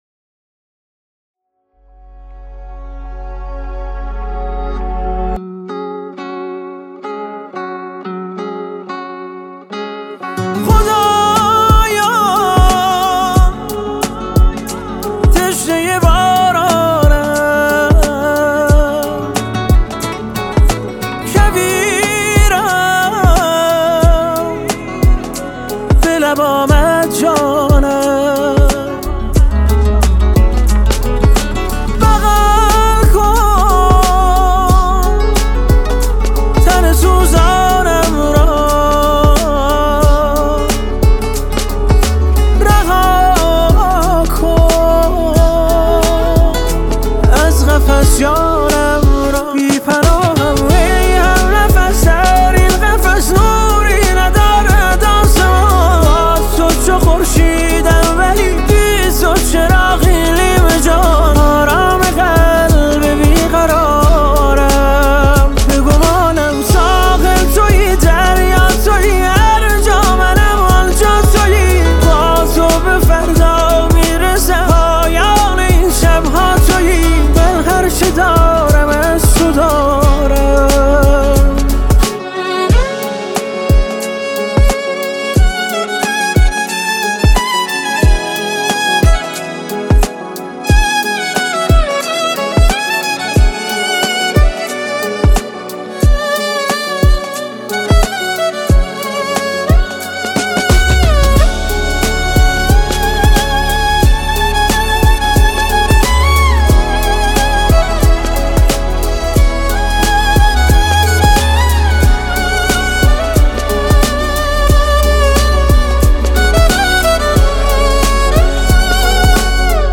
فوق‌العاده احساسی